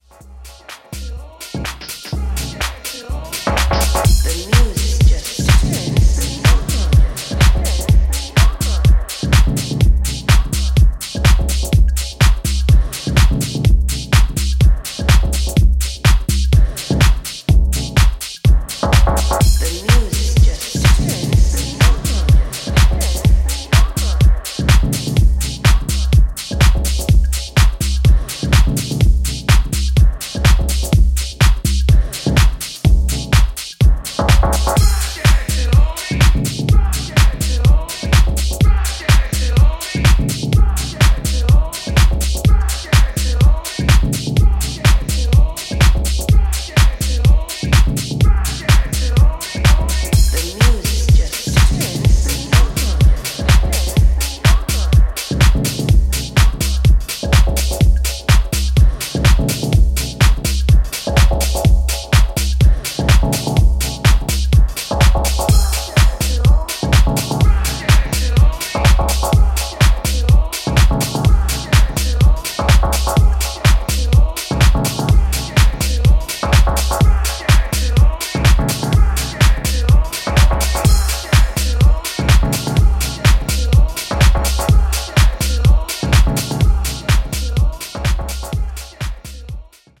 ジャンル(スタイル) DEEP HOUSE